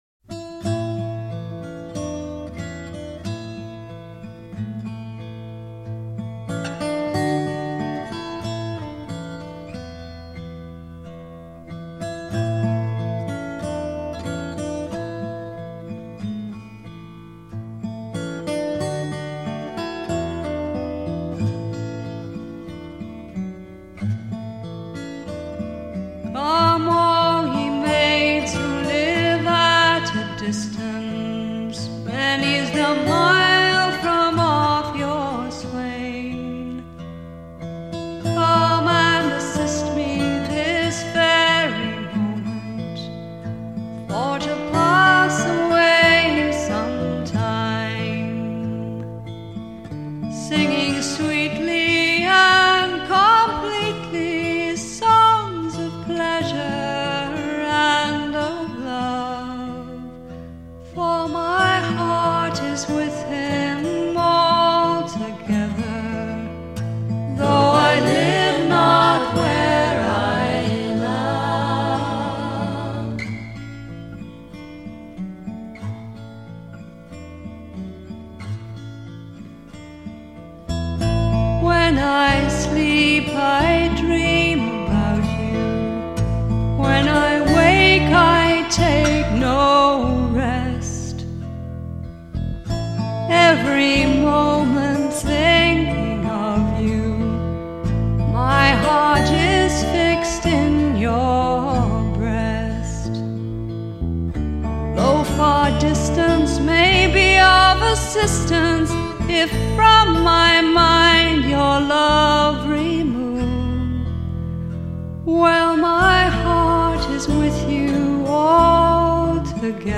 recorded as guest vocalist